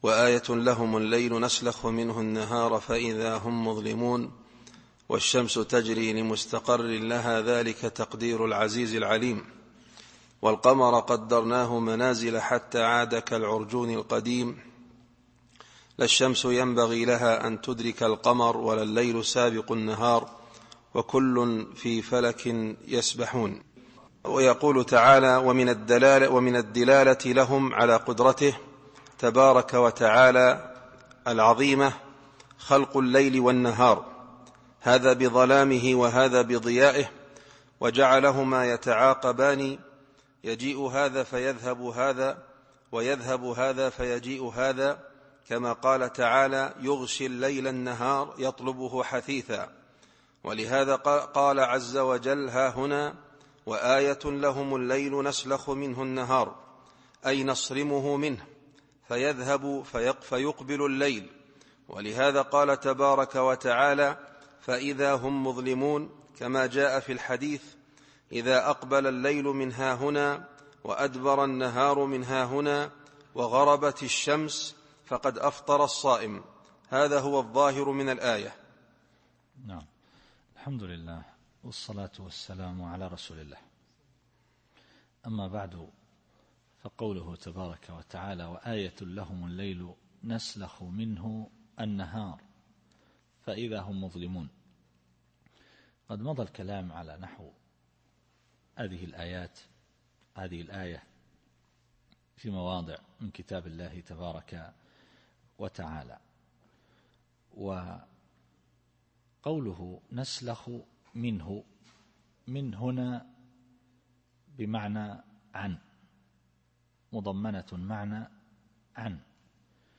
التفسير الصوتي [يس / 37]